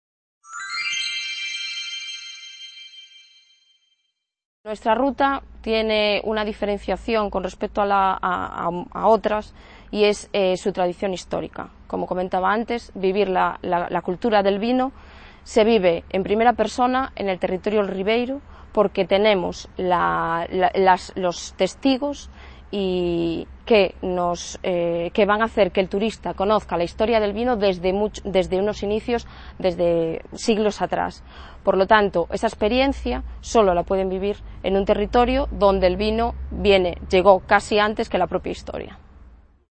C.A. Ponferrada - II Congreso Territorial del Noroeste Ibérico